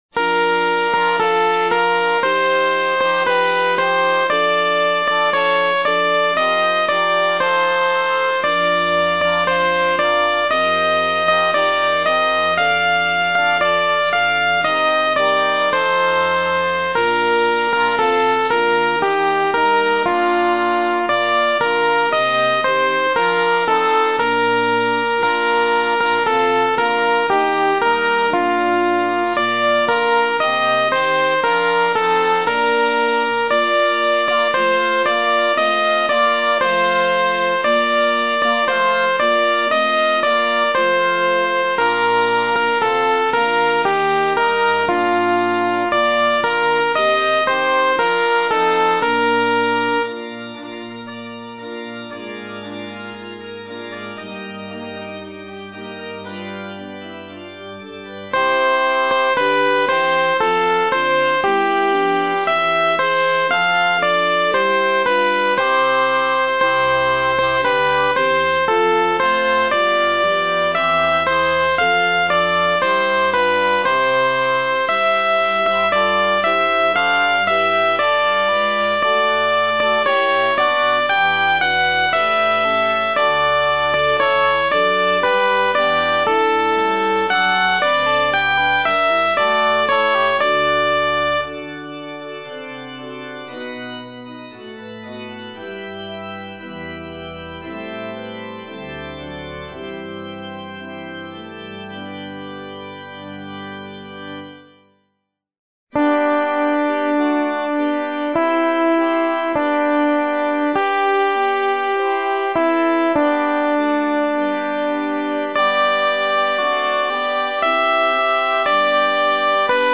ソプラノ（フレットレスバス音）
＊テンポはすべて一定にしてあるので音取りのみに使用し、実際に歌うときは楽譜の指示、指揮者を見る。